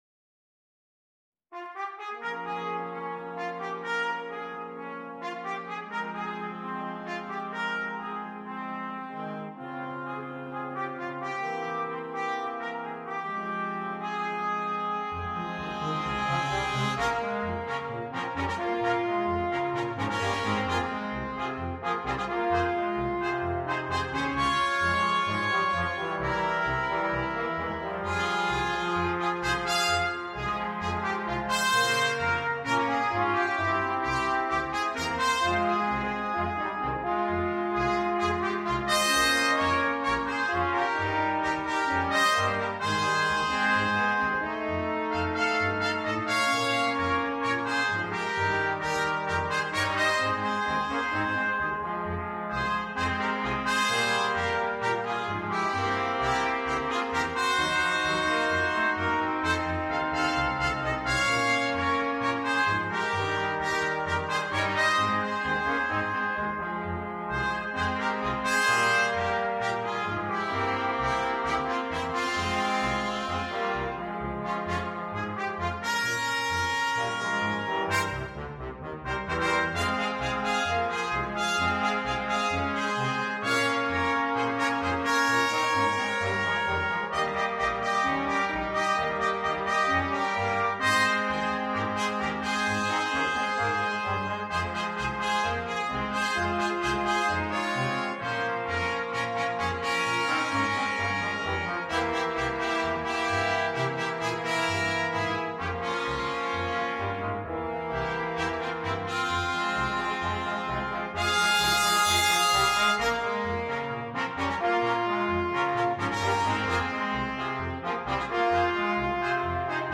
Ноты для брасс-квинтета